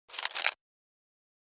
دانلود صدای مزرعه 5 از ساعد نیوز با لینک مستقیم و کیفیت بالا
جلوه های صوتی
برچسب: دانلود آهنگ های افکت صوتی طبیعت و محیط دانلود آلبوم صدای مزرعه روستایی از افکت صوتی طبیعت و محیط